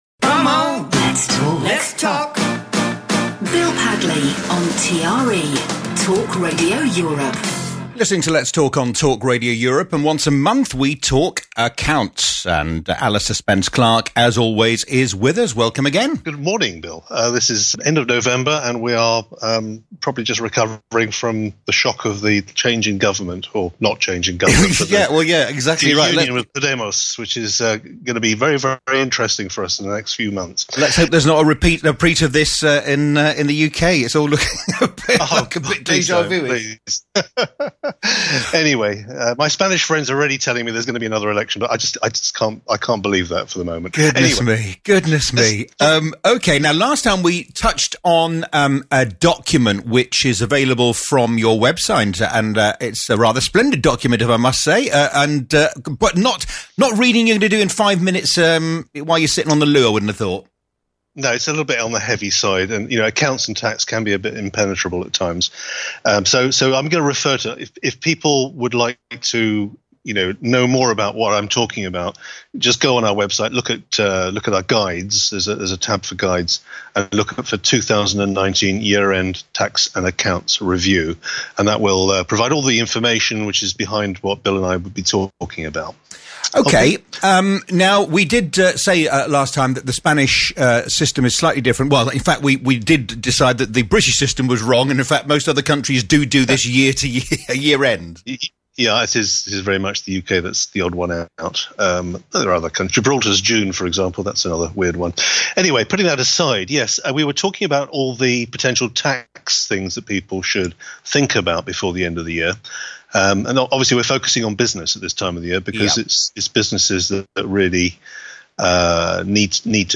Regular interviews